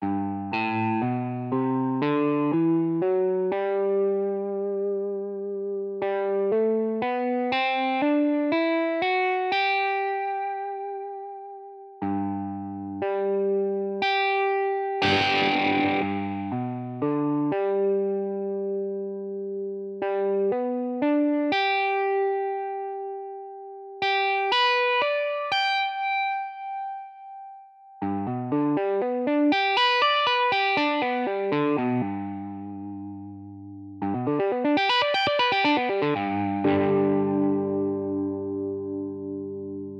• G major scale with 3 notes per string using economy picking.
• G major chord tones separated into 3 octaves including a tapped note.
• Sweep picking the G major chord tones with eighth notes (aka. Sweep Arpeggios)
• Sweep picking the G major chord tones with sixteenth notes
The audio is auto-generated so it’s tonal quality isn’t the best, but it will help you play in perfect time at 120bpm.
Ex-019-Efficient-Speed-Picking-Exercise.mp3